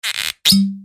Другой вариант звука доставания пробки из бутылки